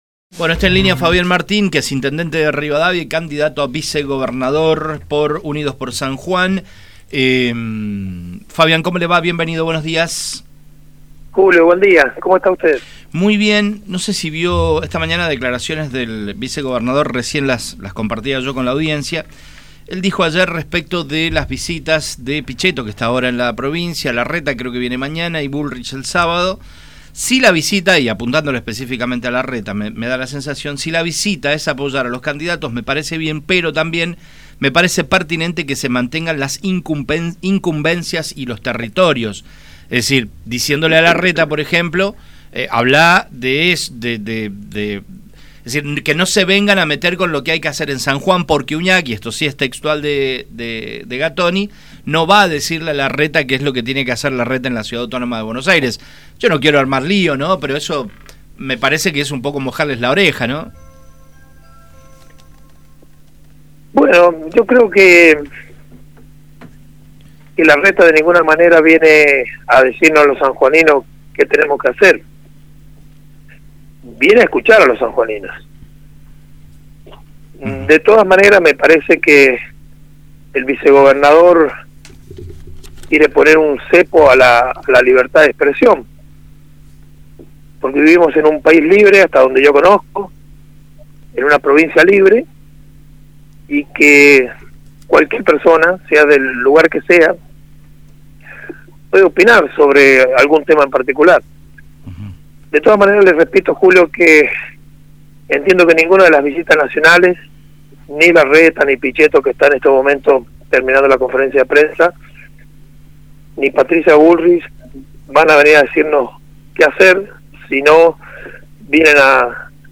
Fabian Martin, actual intendente de la Municipalidad de Rivadavia, en diálogo con Radio Sarmiento, habló sobre las visitas